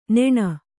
♪ neṇa